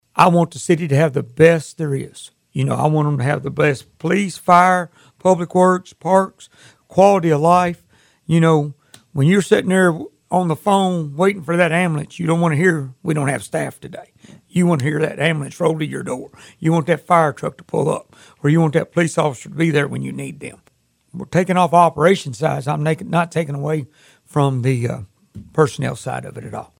The Mayor says he does not want to cut corners on this budget.